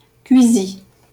Cuisy (French pronunciation: [kɥizi]